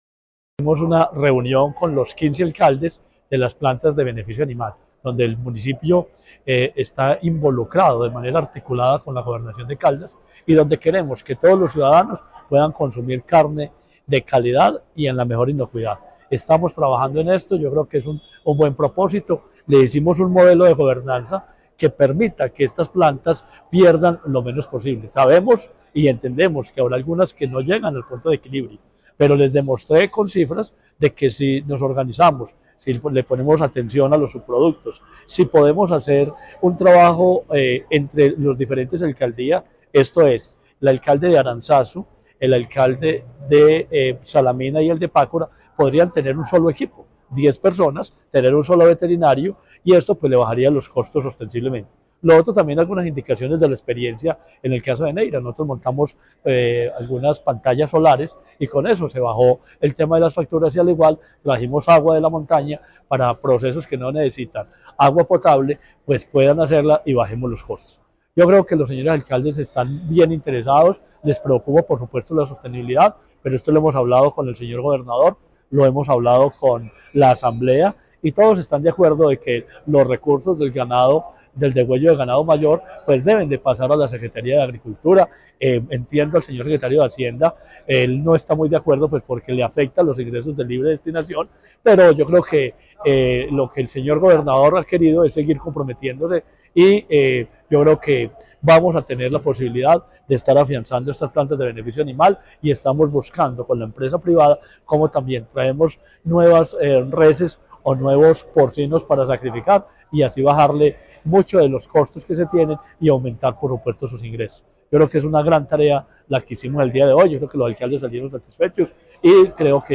Marino Murillo Franco, secretario de Agricultura y Desarrollo Rural de Caldas.